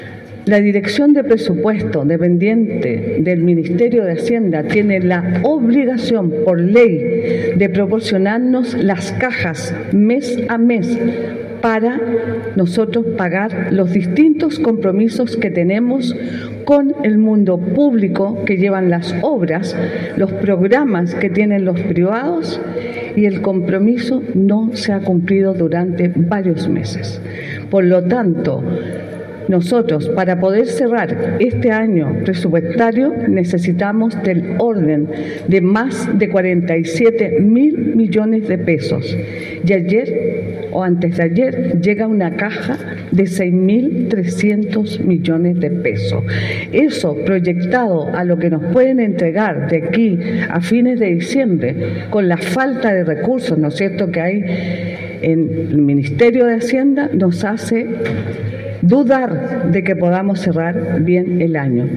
Esta es una situación que no ha sido subsanada por parte de la Dirección de Presupuesto, con lo cual se deben cumplir los compromisos financieros de los distintos programas. La Presidenta de la Comisión de Hacienda indicó que dudan que se pueda cerrar correctamente el año presupuestario.